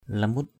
/la-mut/ (d.) (Khm.) mận. plum (tree). phun lamut f~N lm~T cây mặn. plum tree. baoh lamut _b<H lm~T trái mận. plum fruit.